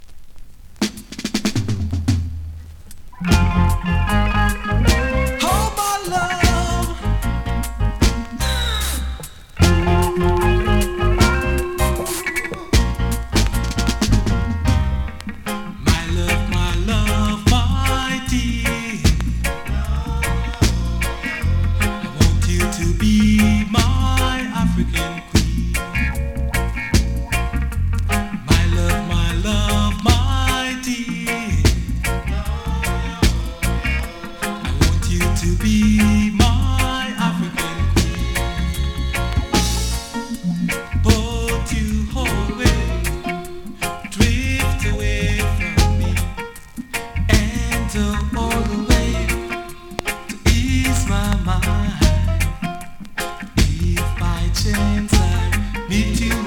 2025!! NEW IN!SKA〜REGGAE
スリキズ、ノイズ比較的少なめで